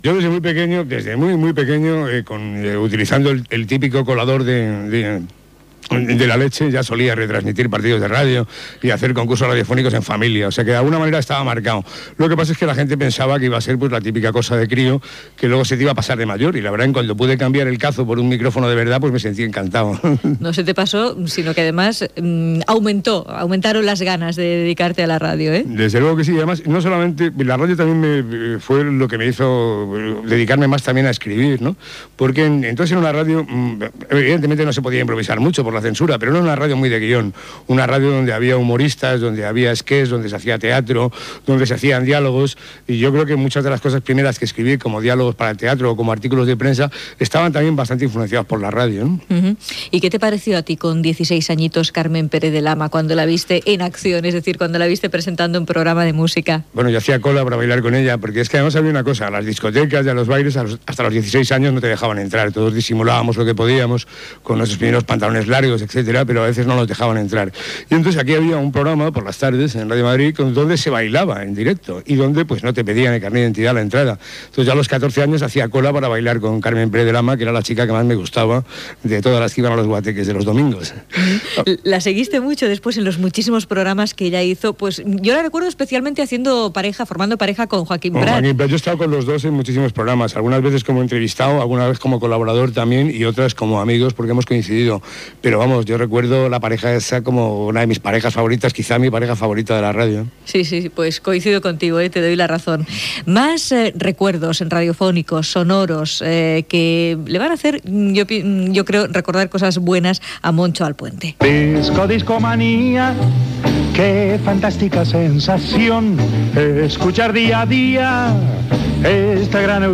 Entrevista al periodista Moncho Alpuente
Divulgació